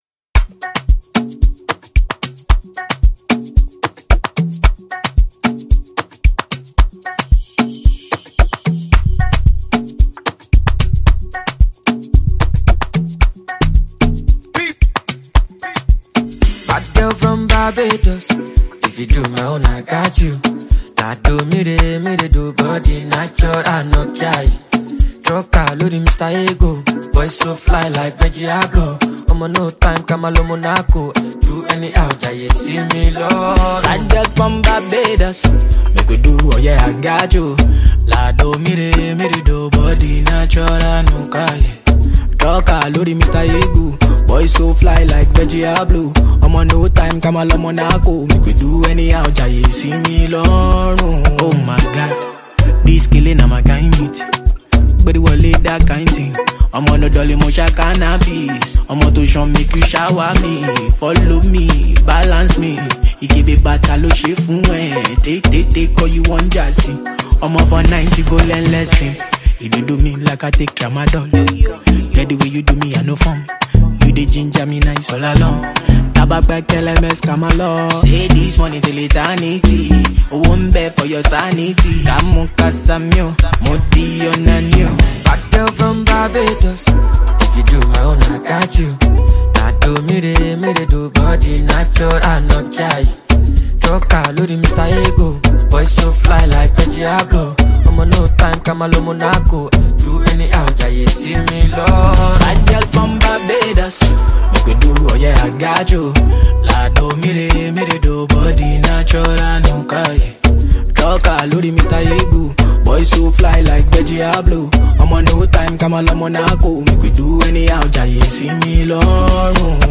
smooth, seductive sounds